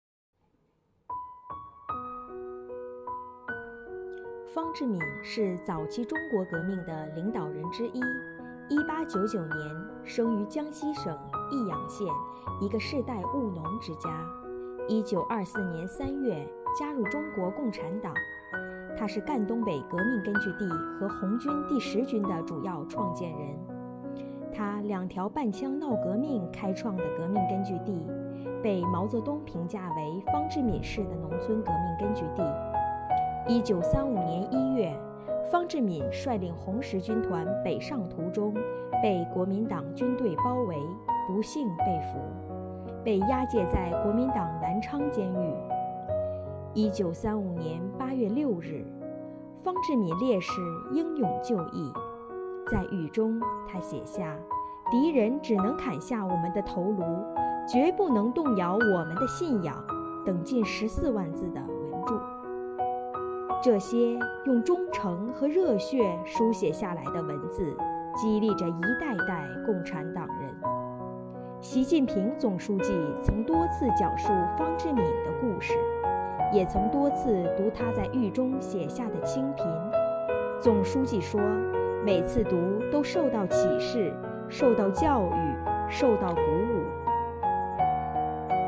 为赓续红色血脉、担当时代责任，图书馆党支部党员讲述党史·初心故事。